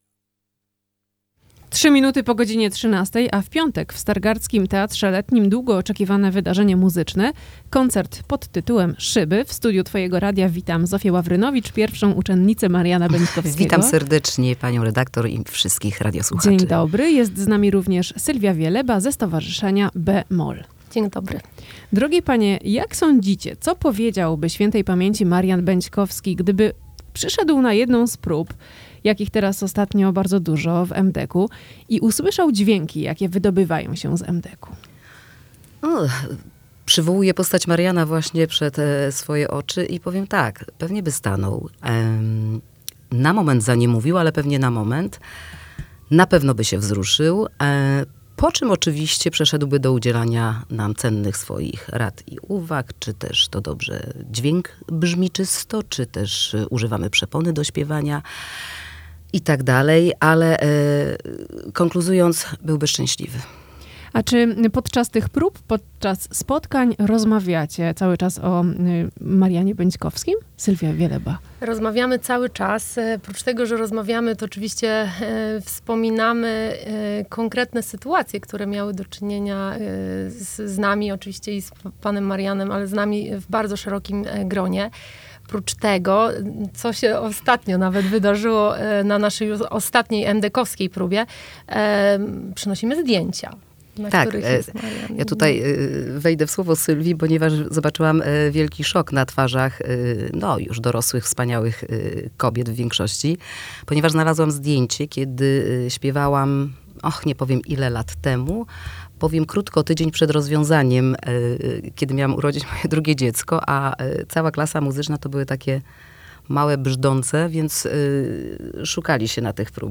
Dziś na antenie Twojego Radia o wydarzeniu rozmawialiśmy